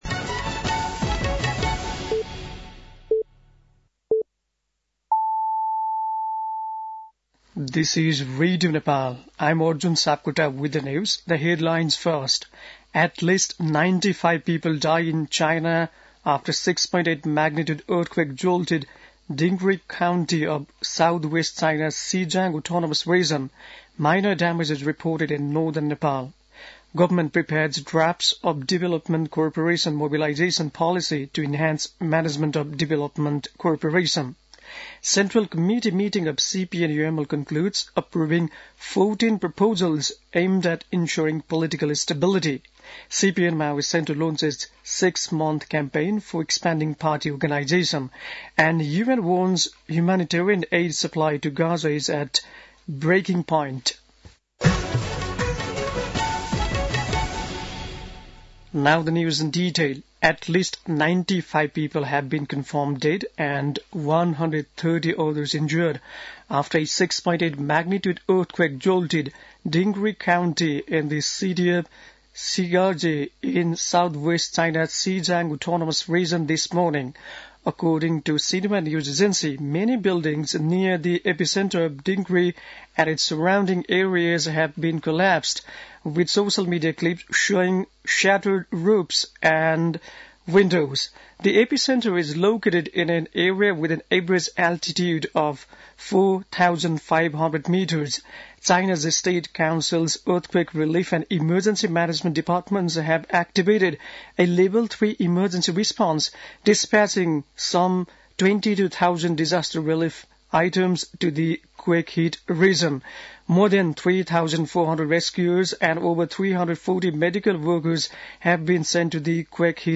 बेलुकी ८ बजेको अङ्ग्रेजी समाचार : २४ पुष , २०८१
8-pm-english-news-9-23.mp3